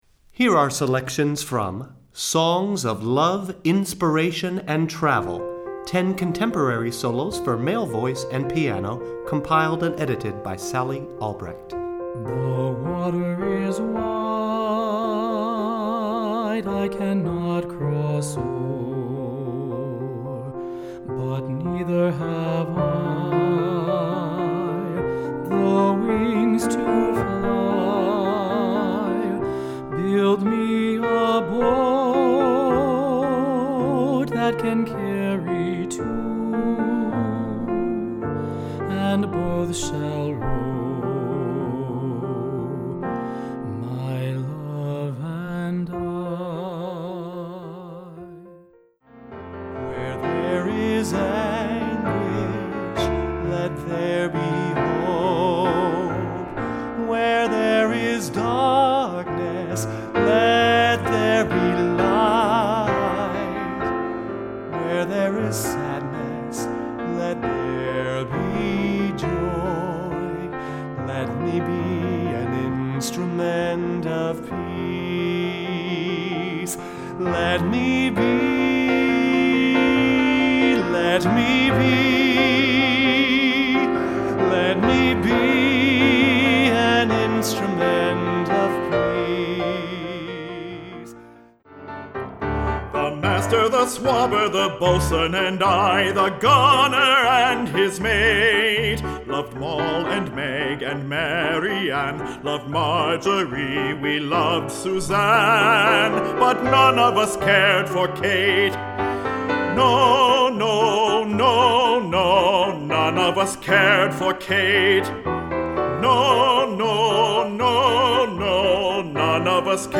Composer: Male Voice
Voicing: Accompaniment CD